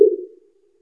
インパルス応答（チューニング前）
400Hz
goSound400HzLVnon.wav